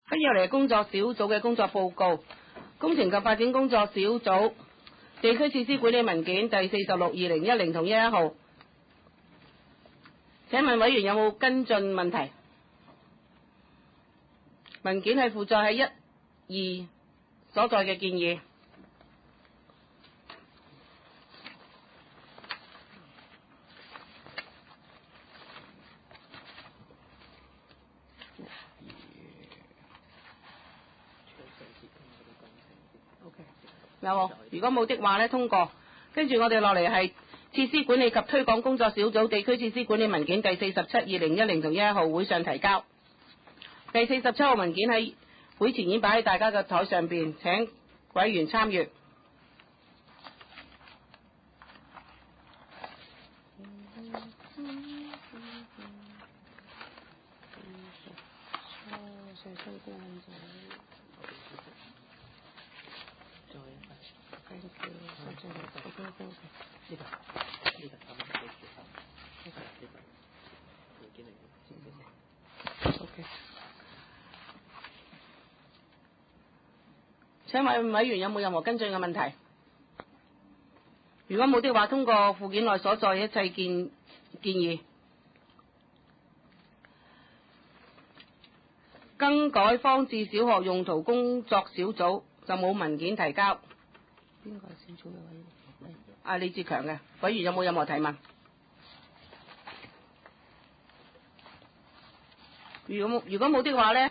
第六次會議(一零/一一)
葵青民政事務處會議室